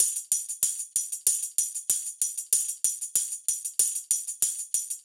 SSF_TambProc1_95-01.wav